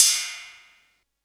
TR 808 Cymbal 02.wav